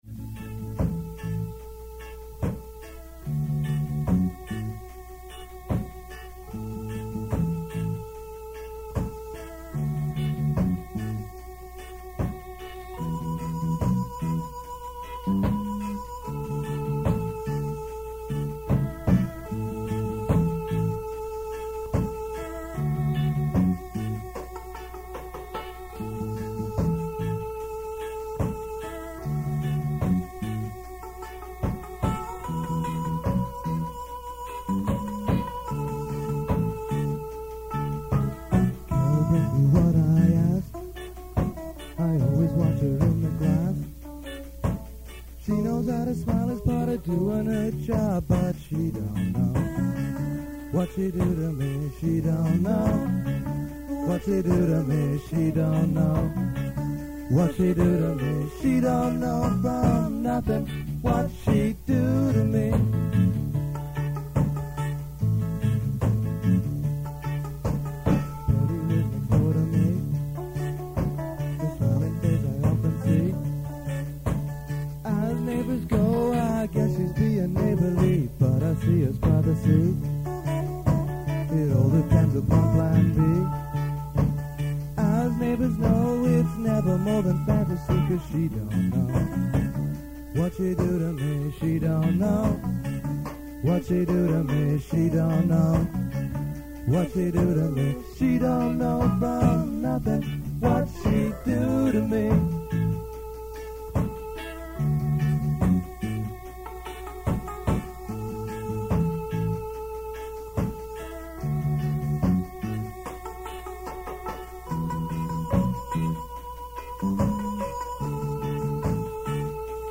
Keep in mind that these are from very old cassette tapes, so sound quailty is definitely an issue.
The band played some covers from The English Beat, Madness and The Specials, but mostly played original material in the style of ska and reggae.
written by Silent Q: from the Rehearsal tape